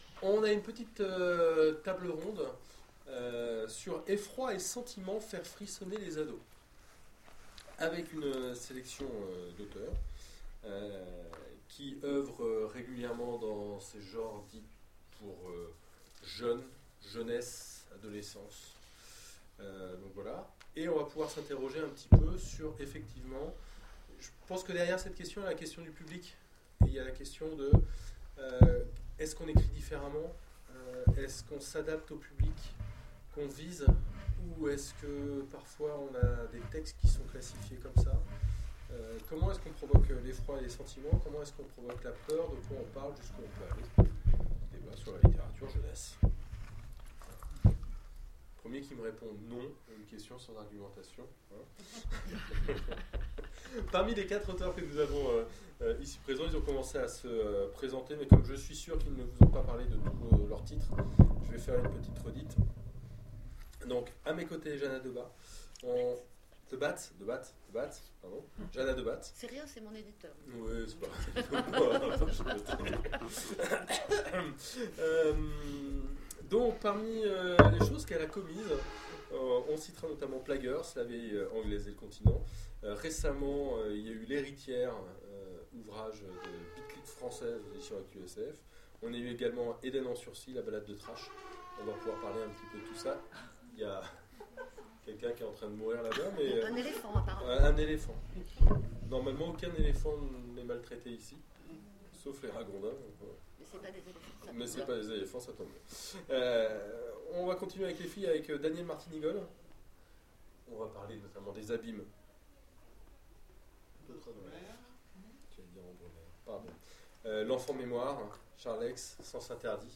Les Oniriques 2015 : Table ronde Effrois et sentiments...
Mots-clés Horreur Littérature jeunesse Conférence Partager cet article